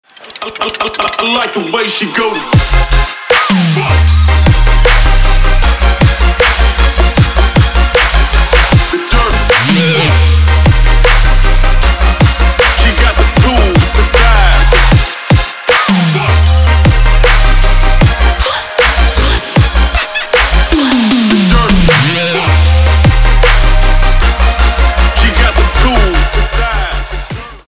• Качество: 256, Stereo
Хип-хоп
Electronic
Trap
club